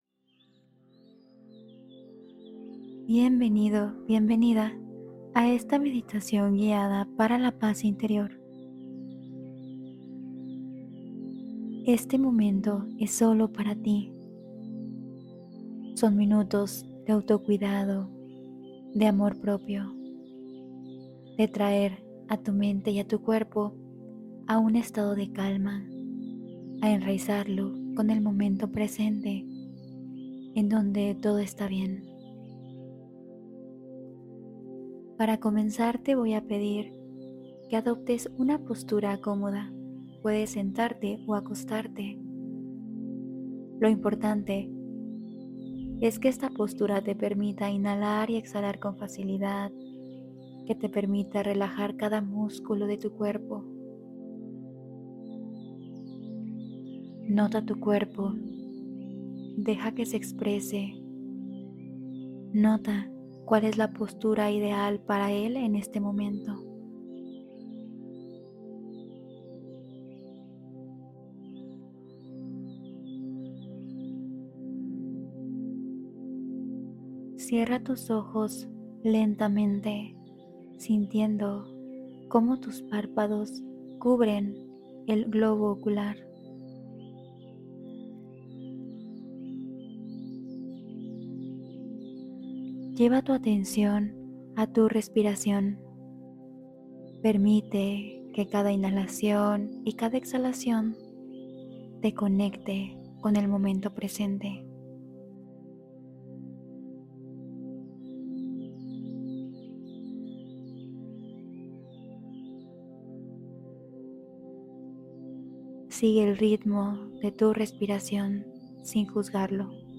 Paz Interior: Meditación Guiada de 10 Minutos ✨